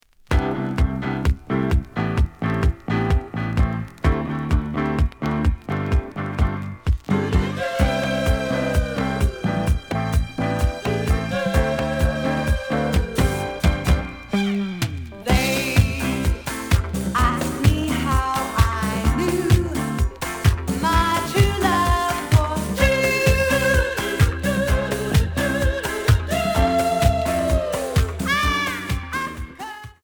(Stereo)
試聴は実際のレコードから録音しています。
●Genre: Disco
●Record Grading: VG+ (盤に若干の歪み。